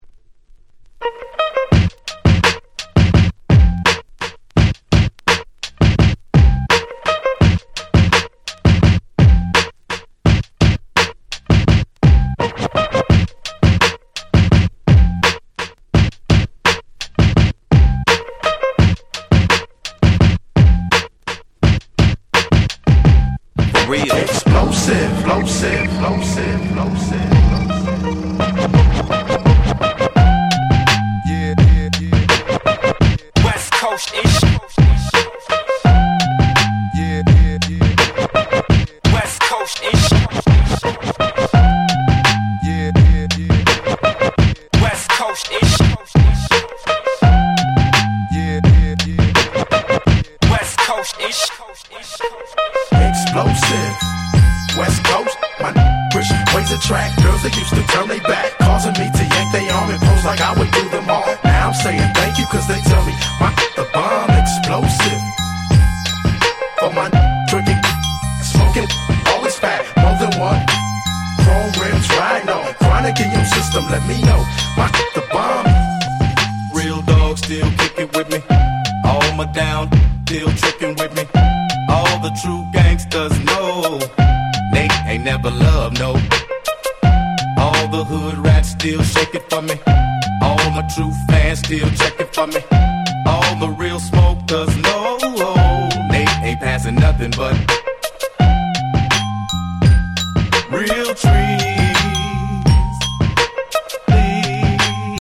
自分が当時好んで使用していた曲を試聴ファイルとして録音しておきました。